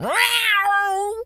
cat_scream_13.wav